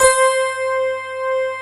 Index of /90_sSampleCDs/USB Soundscan vol.09 - Keyboards Old School [AKAI] 1CD/Partition A/17-FM ELP 5